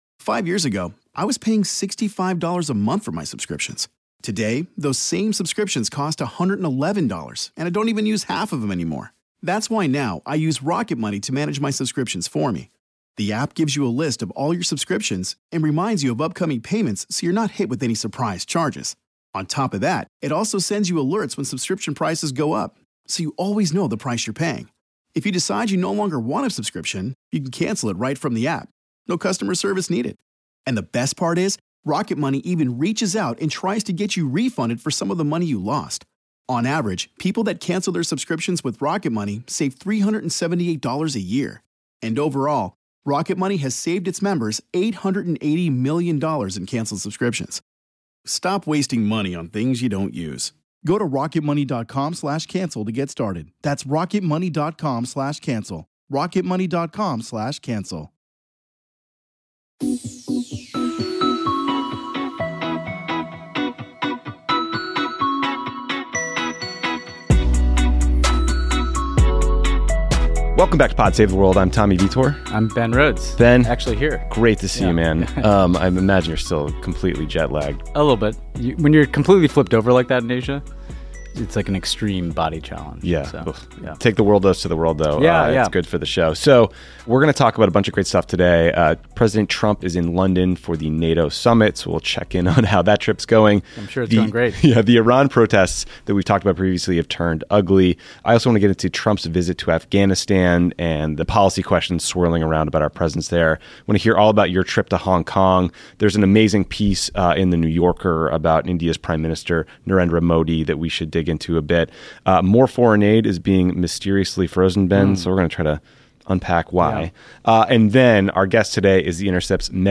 Tommy and Ben talk about Trump’s trip to London for the NATO summit, including his dustup with the French President and the health of NATO in 2019. Then they discuss the Iranian government’s crackdown on protestors, Trump’s visit to Afghanistan, Ben’s trip to Hong Kong, the expanding trade war, amazing reporting about India’s Prime Minister, and why US foreign aid keeps getting mysteriously frozen. Then The Intercept’s Mehdi Hasan joins to talk about the upcoming British parliamentary elections, the London Bridge attack, and Brexit.